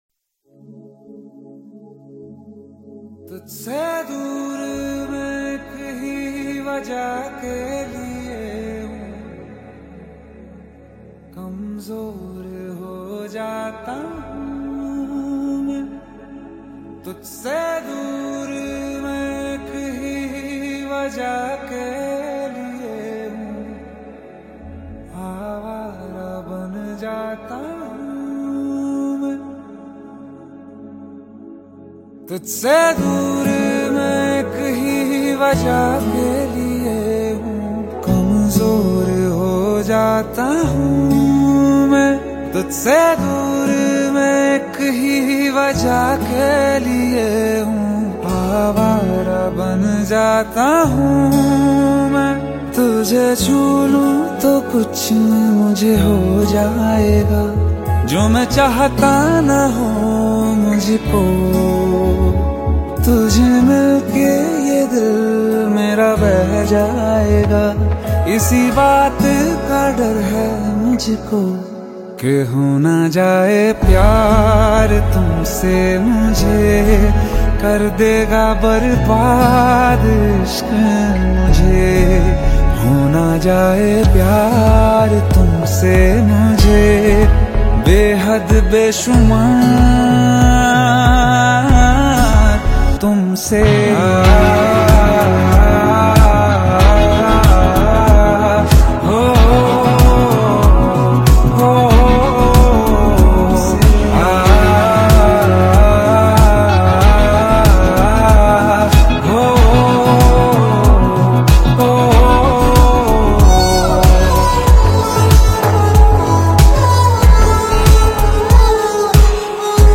Bollywood Songs